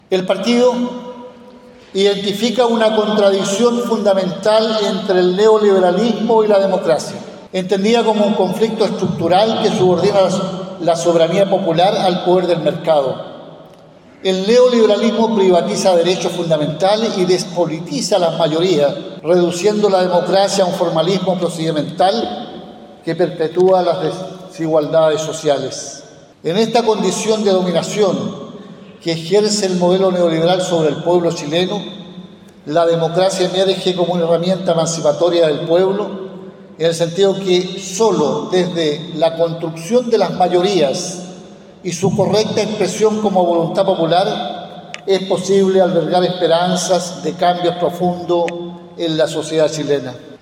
Durante la inauguración, el Presidente del Partido Comunista Lautaro Carmona, realizó un discurso en dónde abarcó de manera detallada los puntos más importantes de la política y el debate de los comunistas.